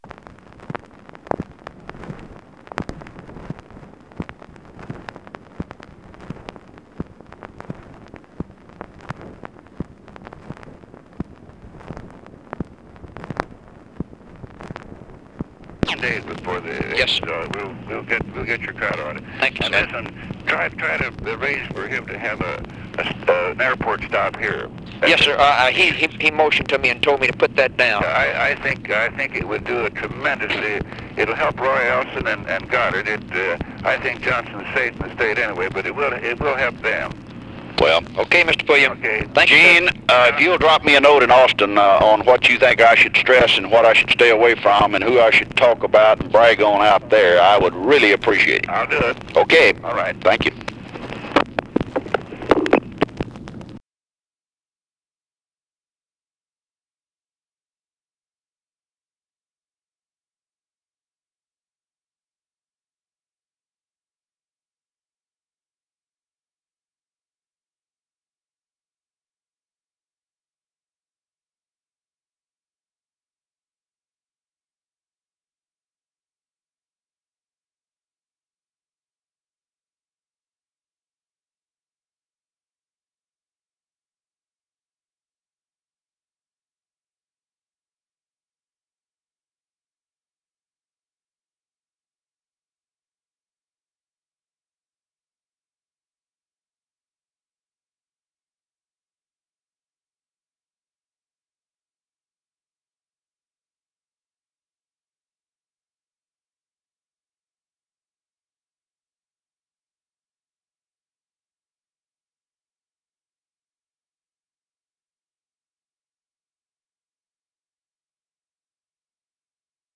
Conversation with EUGENE PULLIAM and JOHN CONNALLY, September 23, 1964
Secret White House Tapes